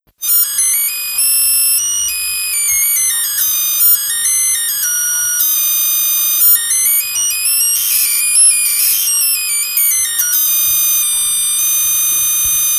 Das klingt verdammt nach hörbar gemachter HF, die ein Computer währen seiner Arbeit erzeugt.
Hinweis 1: Die Melodie wird weder von einem Mensch noch ein Tier gespielt.
zeigt Brummen bei 50 Hz und 77 Hz.
Ansonsten geht die Kakophonie von 1,4 kHz bis 14 kHz im Frequenzbereich. Und je höher die Frequenz ist, desto höher ist auch der Pegel.
Hinweis 2: Ein Mikroprozessor ist beteiligt, die Töne werden aber nicht durch ein Soundprogramm gespielt.
Hinweis 3: Die Töne wurden mit einem Mikrofon aufgenommen, entspringen aber keinem Lautsprecher und auch keinem anderen Akustikbauteil.